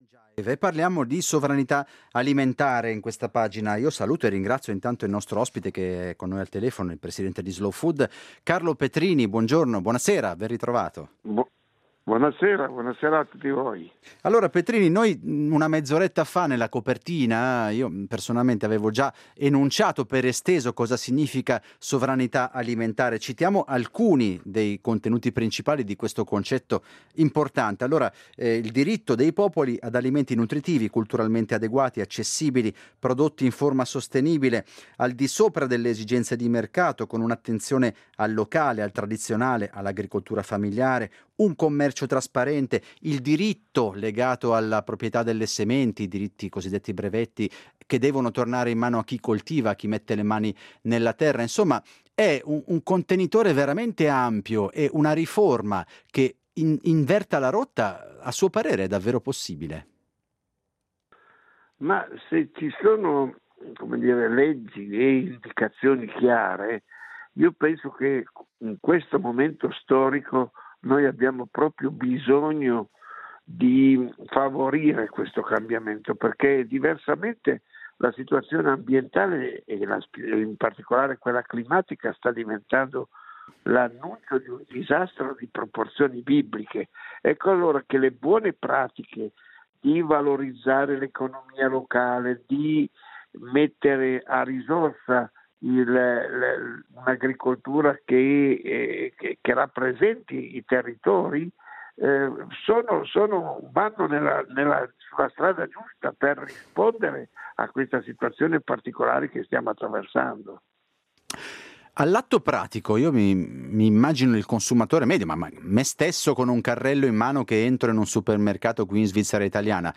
Il principio della sovranità alimentare verrà presto sottoposto a votazione popolare per essere, nel caso, inserito nella costituzione del Canton Ticino. Cosa significa questo principio di cui si parla molto anche in chiave internazionale, e come potrebbe confliggere con il commercio globale e modificare i cicli della produzione alimentare? Ne parliamo con Carlo Petrini, presidente di Slow Food.